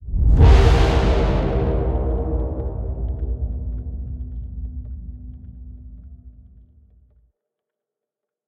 impact_horn_04.ogg